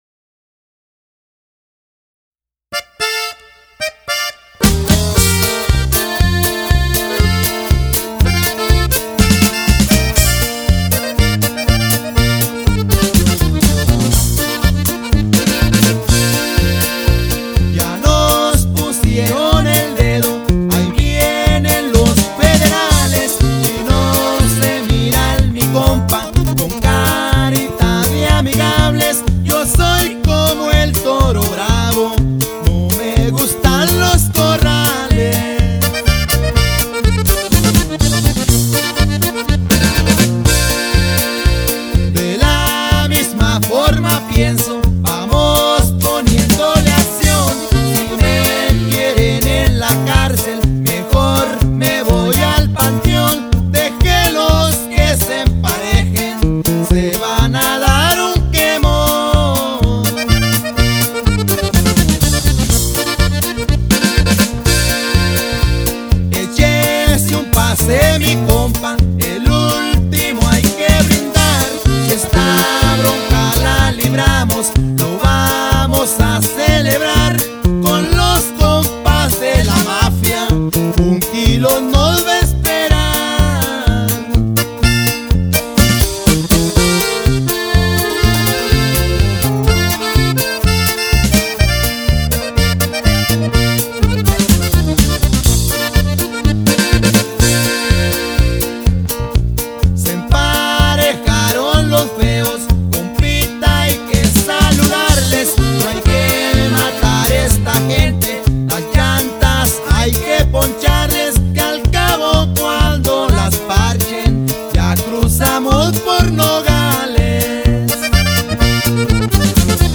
ACORDEON
BAJO SEXTO
BAJO ELECTRICO
BATERIA